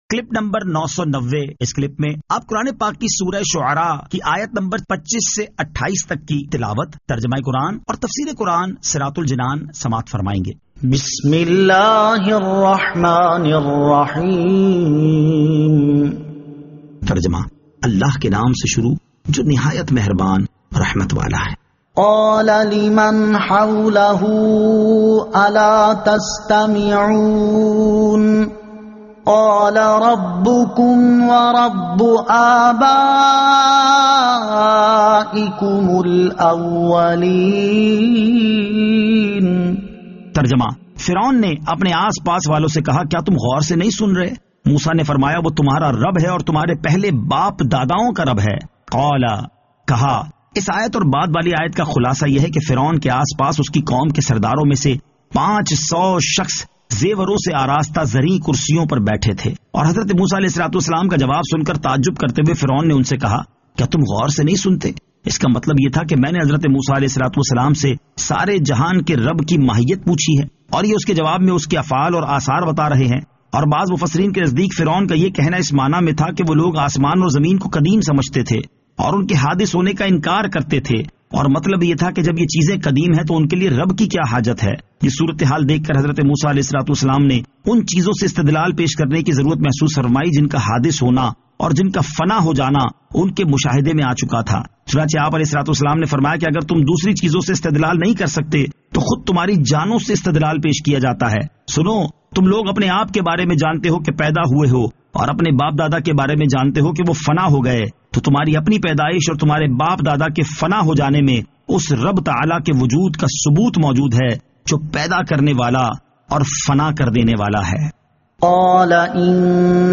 Surah Ash-Shu'ara 25 To 28 Tilawat , Tarjama , Tafseer